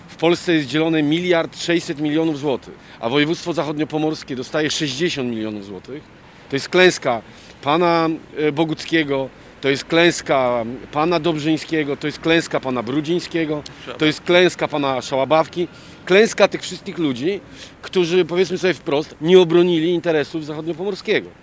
SZCZ-Nitras-Konferencja.mp3